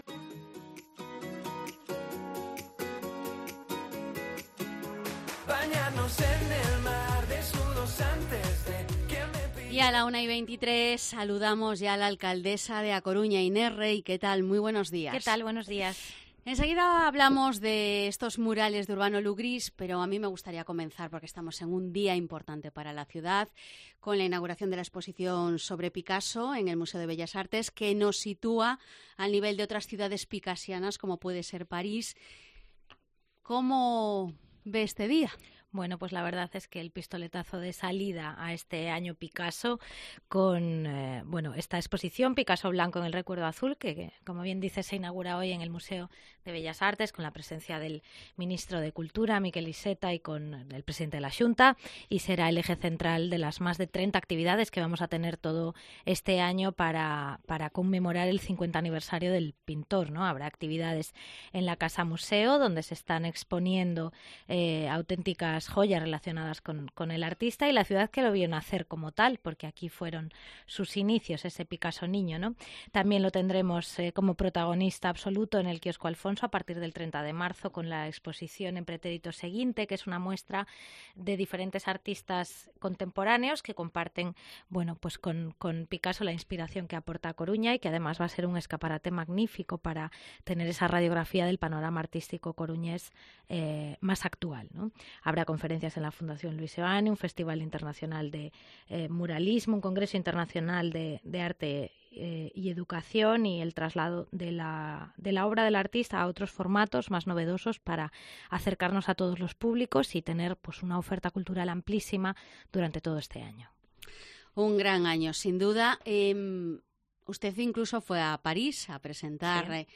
Entrevista a la alcaldesa de A Coruña, Inés Rey